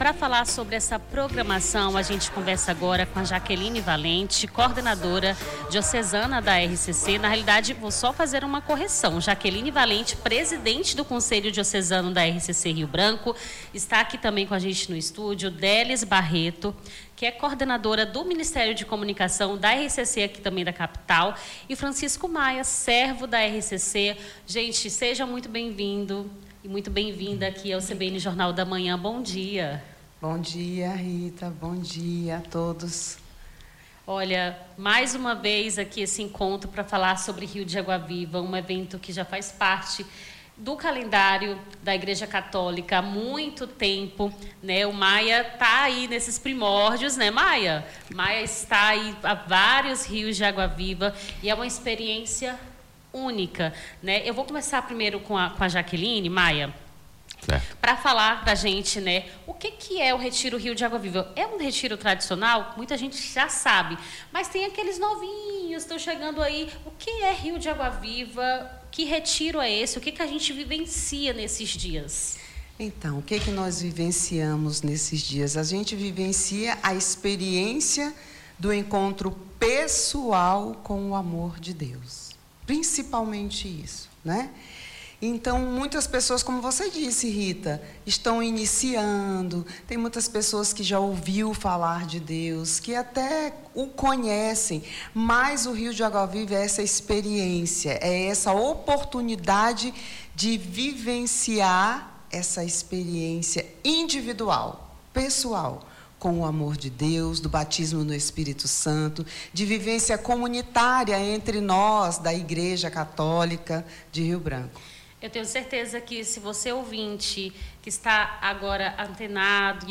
CENSURA---ENTREVISTA-RIO-DE-AGUA-VIVA---13-02-26 Digite seu texto aqui...